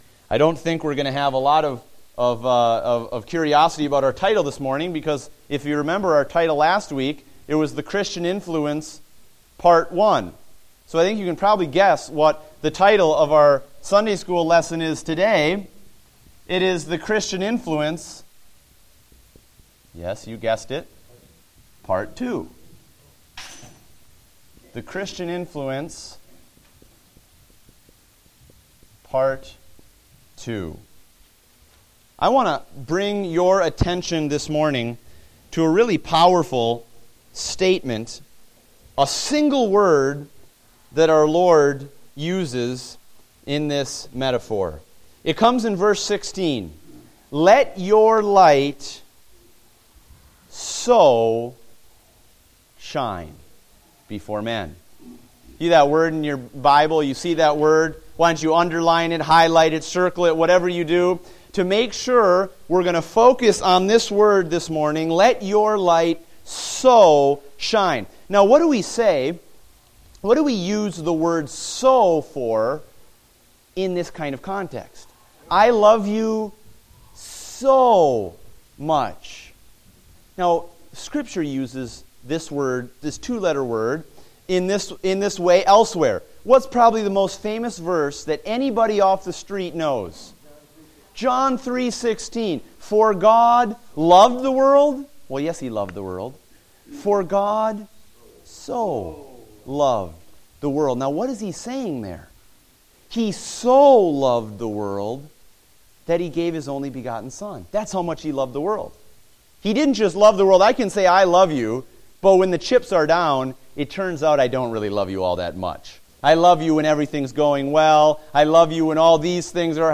Date: May 17, 2015 (Adult Sunday School)